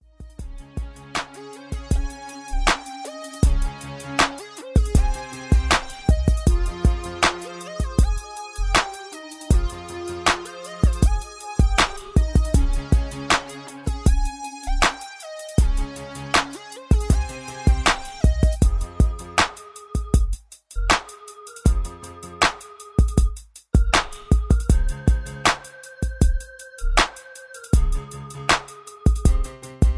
Laid Back Dirty South Beat